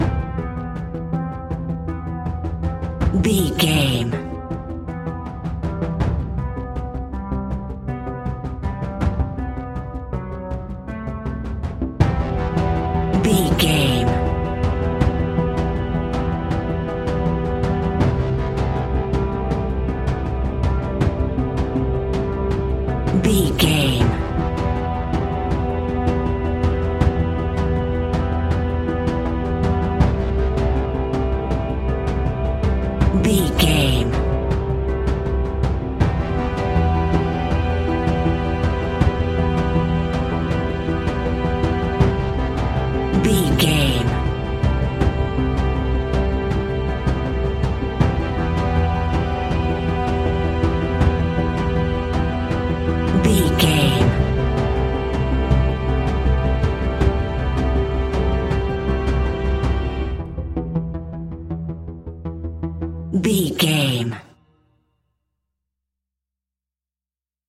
In-crescendo
Thriller
Aeolian/Minor
ominous
eerie
synthesizer
percussion
horror music